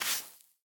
brushing_sand3.ogg